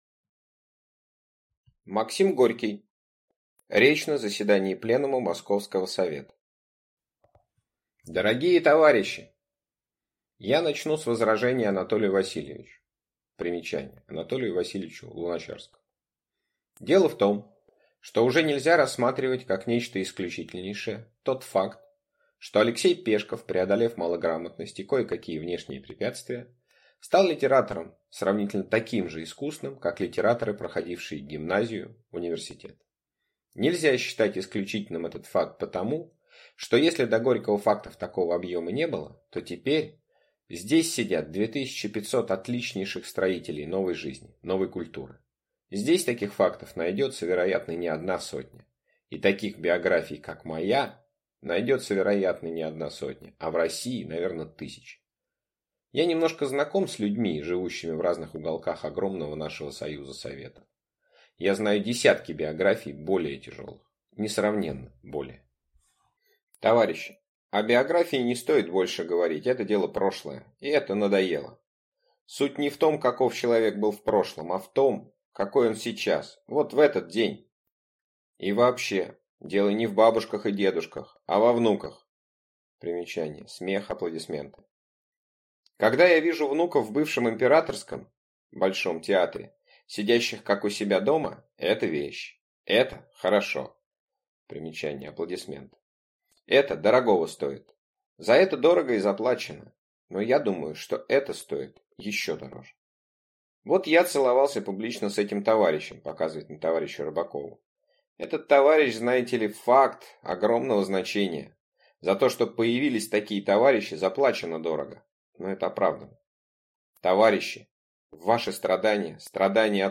Речь на заседании пленума Московского Совета – Максим Горький